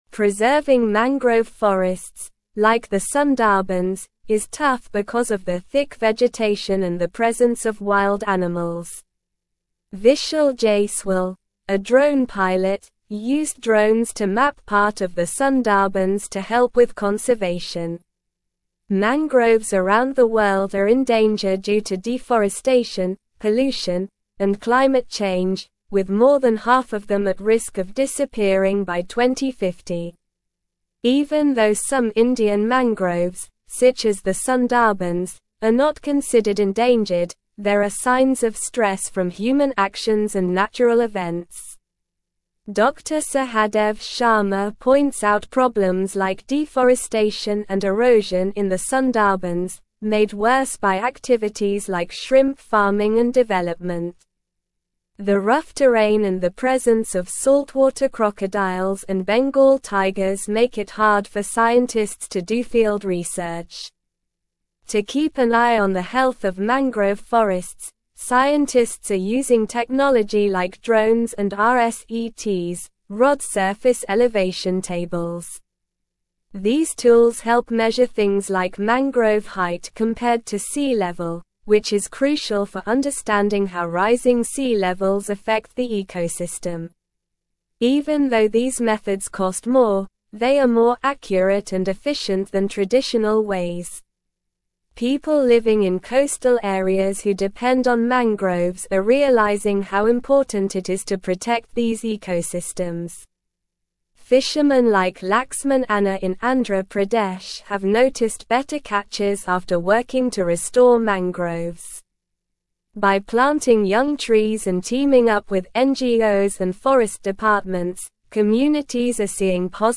Slow
English-Newsroom-Upper-Intermediate-SLOW-Reading-Mapping-Sundarbans-Drones-Preserve-Worlds-Largest-Mangrove-Forest.mp3